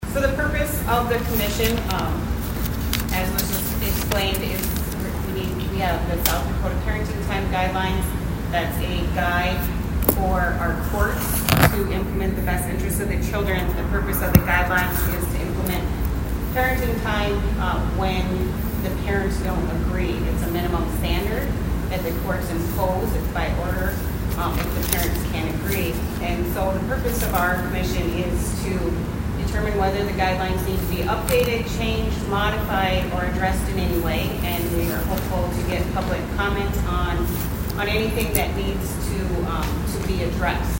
ABERDEEN, S.D.(HubCityRadio)- On Monday night, the State Supreme Court Commission dealing with parenting time guidelines met in Aberdeen.
The chair of the committee, 6th Judicial Circuit Court Judge Christina Klinger describe the purpose of the committee.
Christina-Klinger-1.mp3